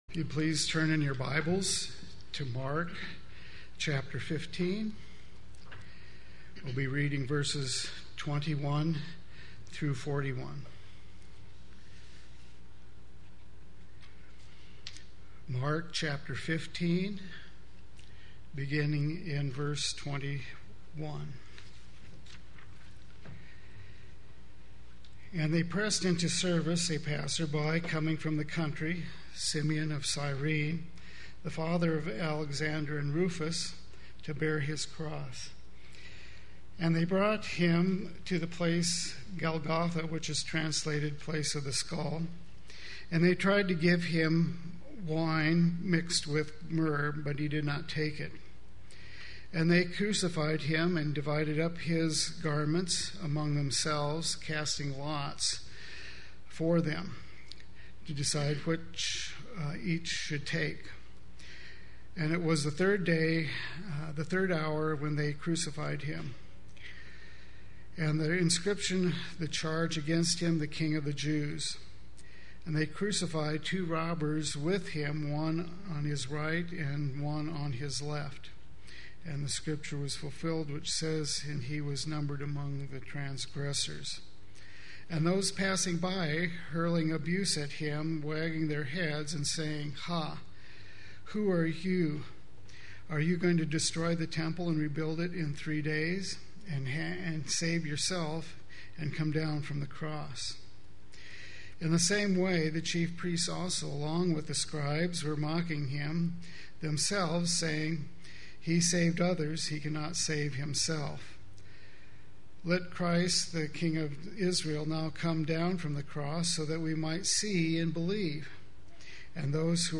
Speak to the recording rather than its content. The Crucifixion of Jesus Sunday Worship